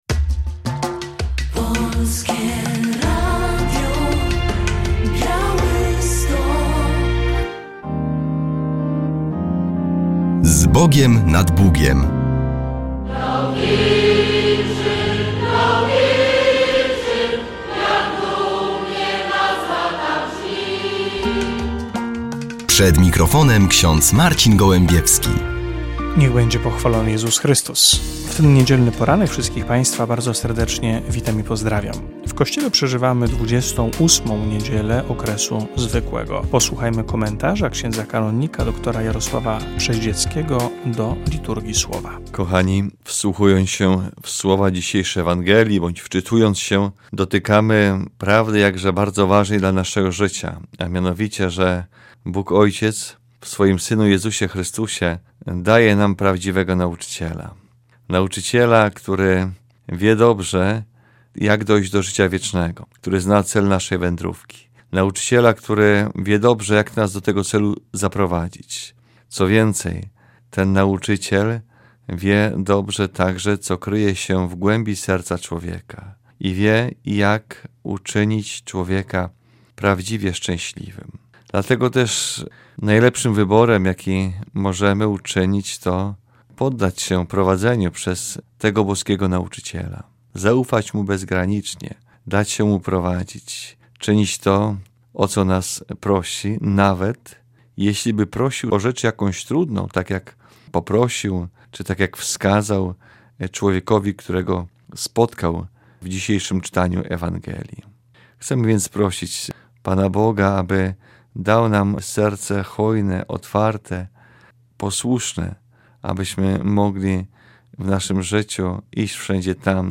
W audycji relacja z obłóczyn trzech alumnów Wyższego Seminarium Duchownego w Drohiczynie.